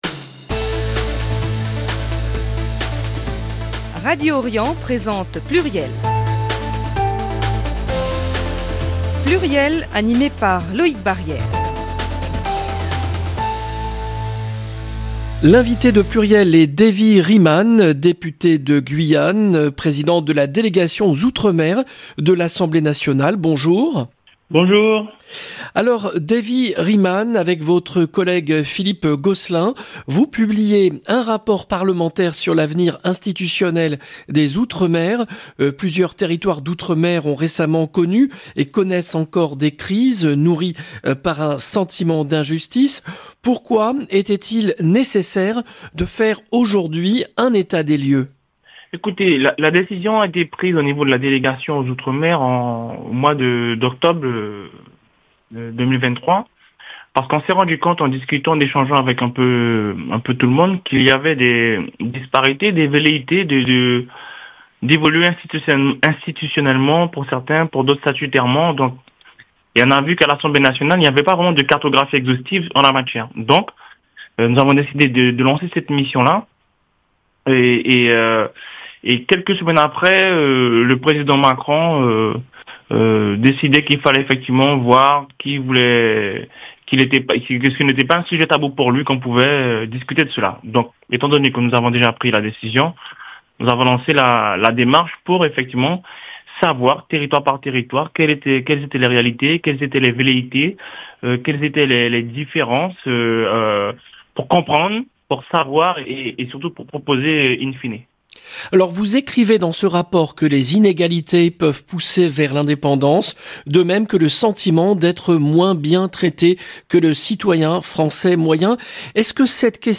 PLURIEL, le rendez-vous politique du mercredi 4 décembre 2024